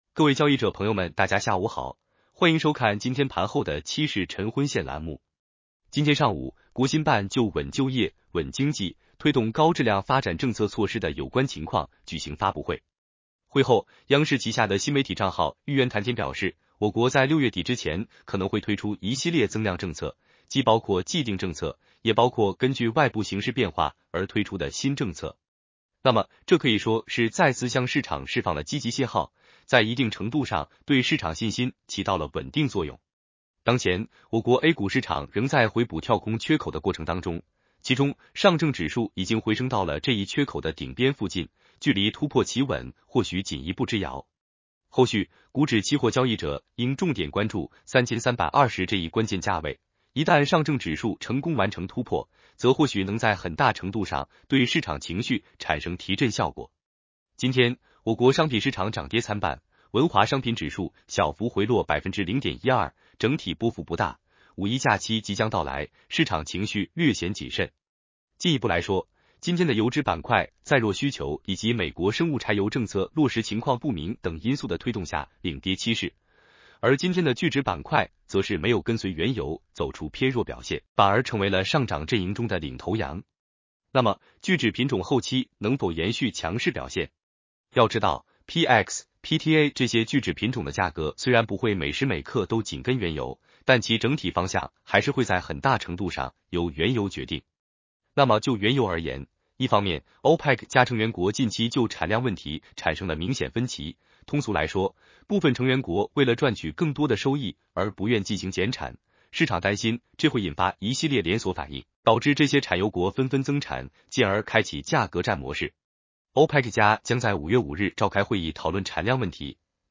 男生普通话版 下载mp3